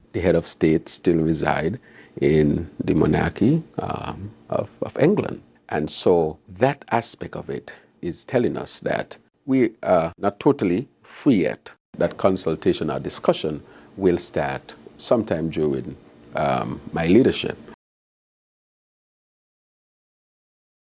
PM Drew said he wants to give people a say, as to who the head of state should be.
Prime Minister, Dr. Terrance Drew.